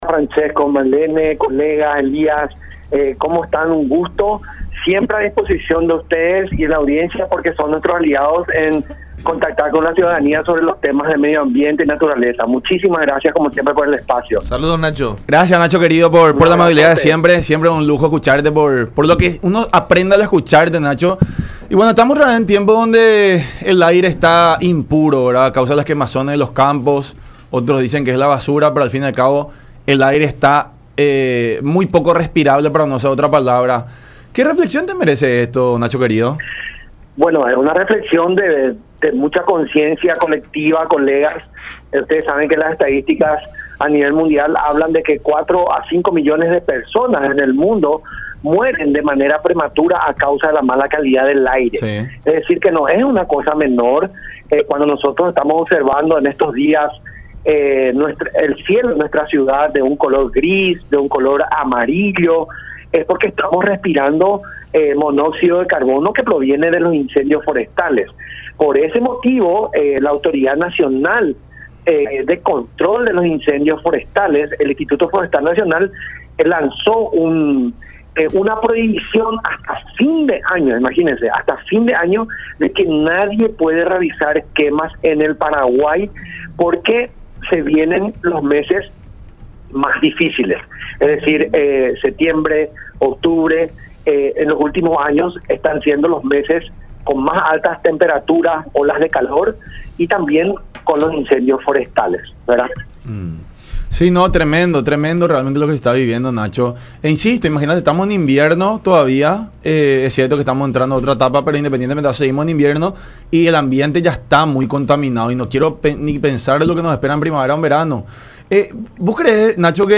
charla con La Unión Hace La Fuerza por Unión TV y radio La Unión